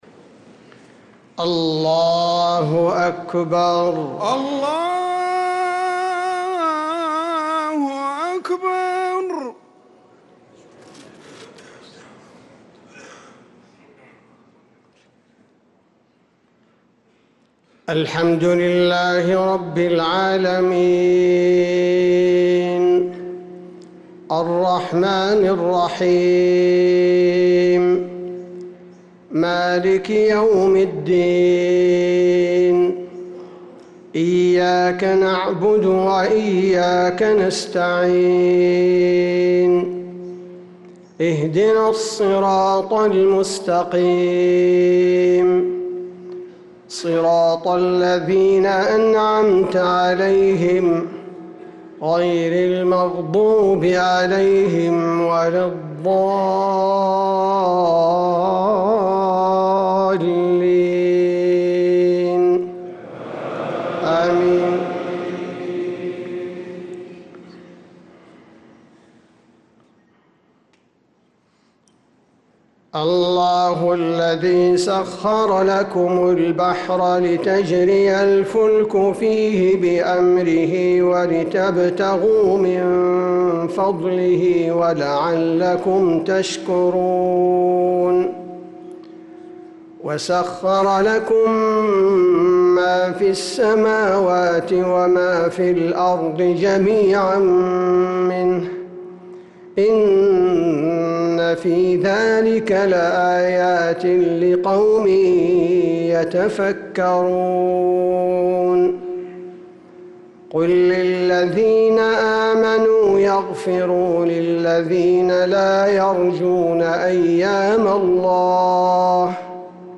صلاة المغرب للقارئ عبدالباري الثبيتي 16 رجب 1446 هـ